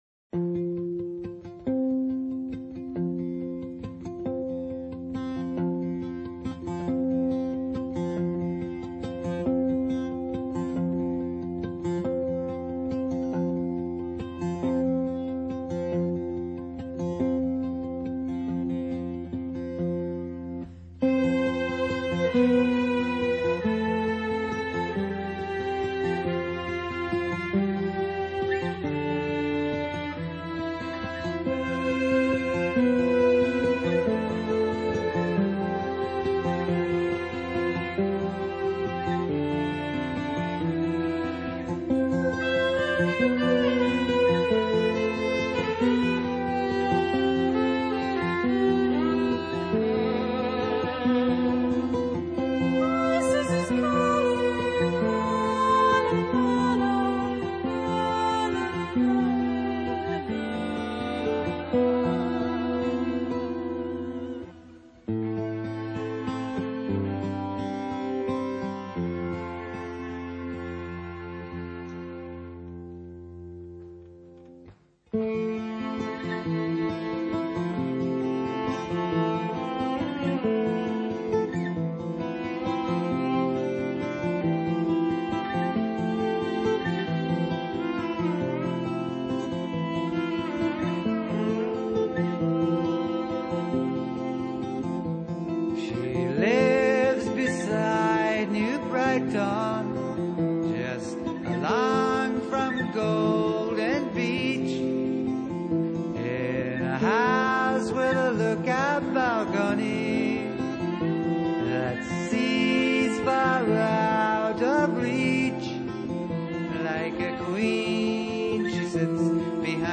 progressive , rock